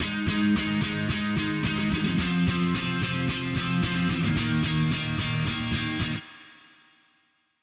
GuitarElectricPump
GuitarElectricPump.mp3